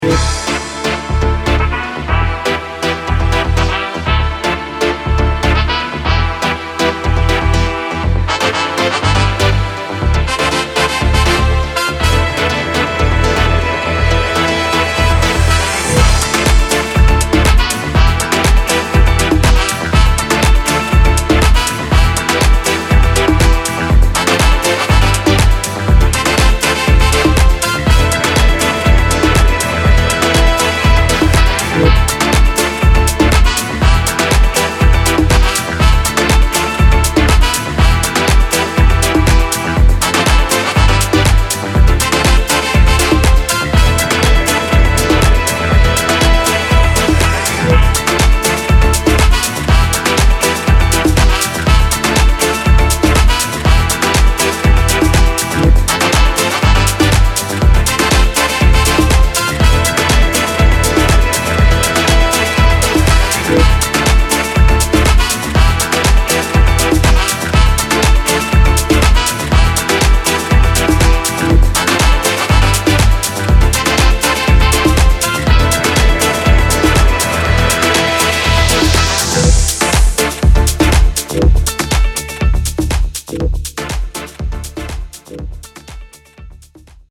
Vocals
Keyboards
Drums